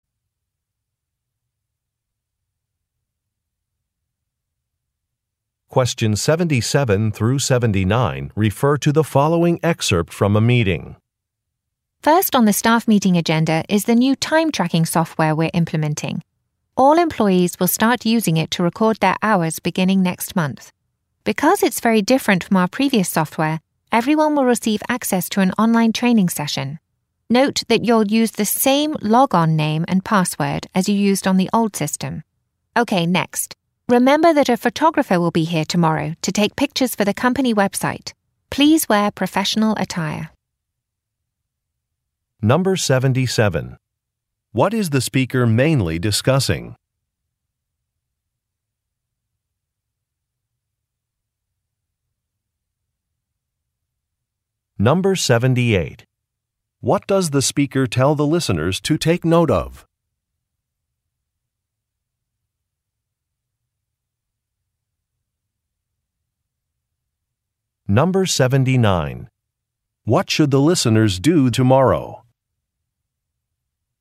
Question 77 - 79 refer to following talk: